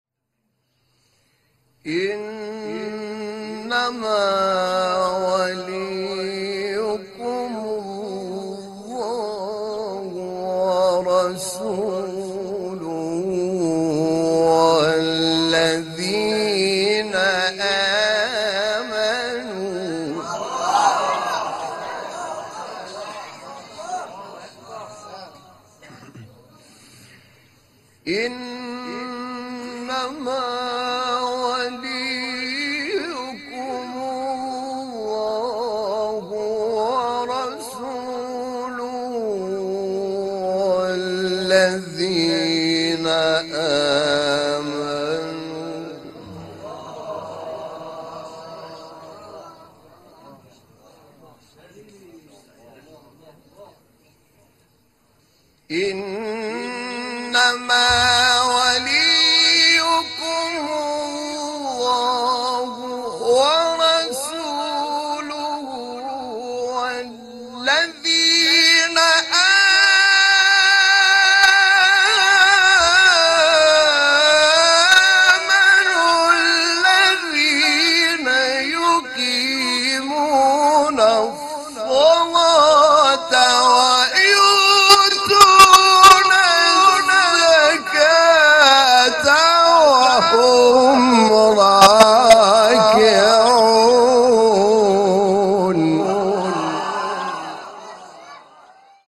تلاوت آیه ولایت با صوت حامد شاکرنژاد
برچسب ها: مقاطع صوتی از تلاوت ، آیه ولایت ، فرازی از تلاوت آیه ولایت ، تلاوت آیه ولایت از قاری مصری ، تلاوت آیه ولایت از قاری ایرانی